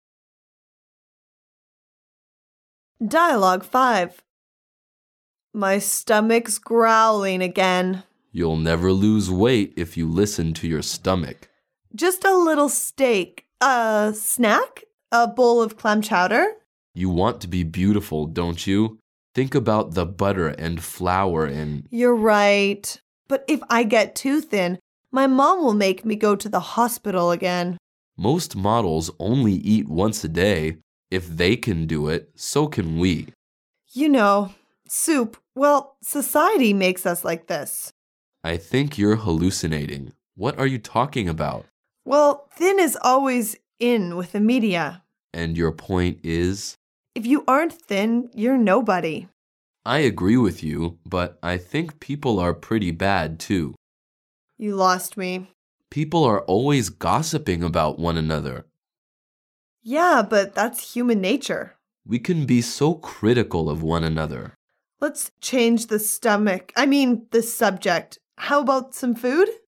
Dialouge 5